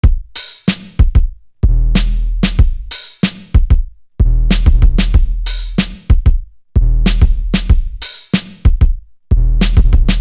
Bang Bang Drum.wav